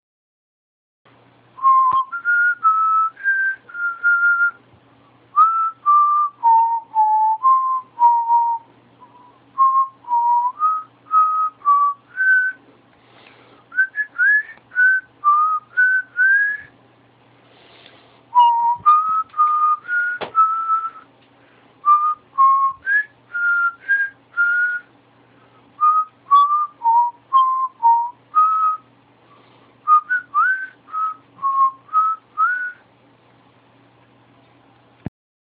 Насвистал концовку программы